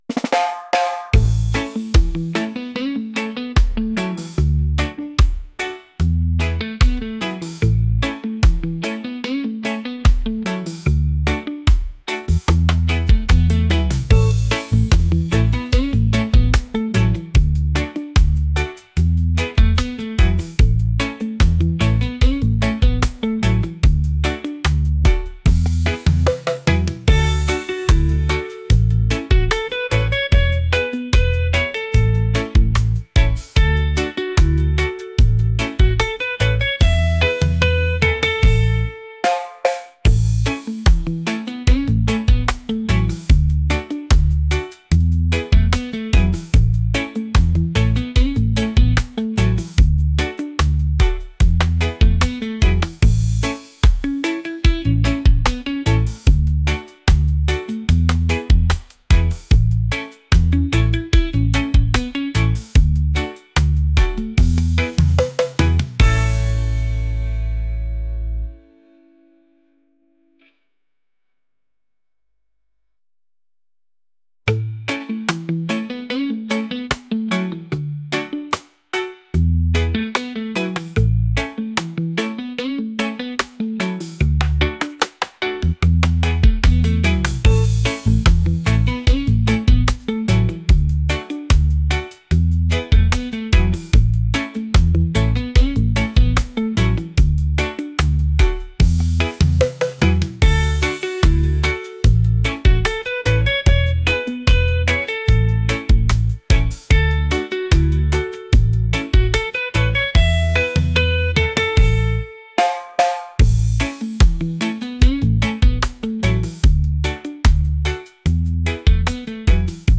catchy | laid-back | reggae